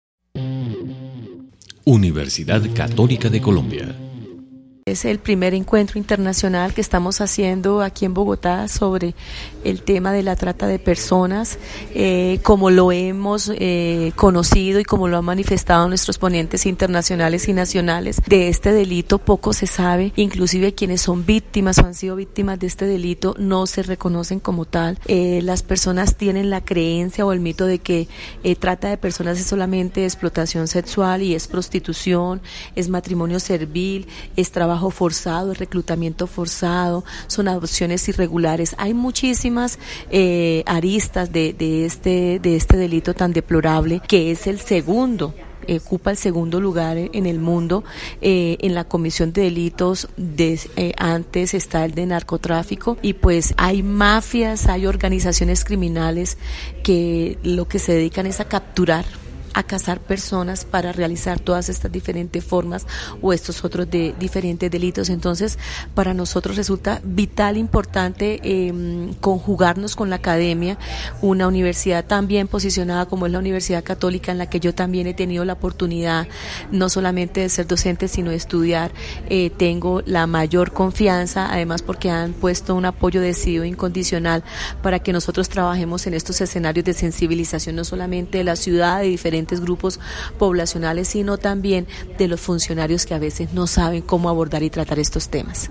En las instalaciones de la Universidad Católica de Colombia se realizó el pasado 1 de agosto, el Primer Encuentro Internacional de Lucha contra la Trata de Personas denominado: Violencias Invisibles que se Tejen en Bogotá – “La Trata Captura”, eventó que contó con la participación y colaboración de la Facultad de Derecho, Extensión Universitaria y la Personería de Bogotá.
Dra. Carmen Tersa Castañeda Villamizar, personera de Bogotá, entregando las palabras de apertura del evento.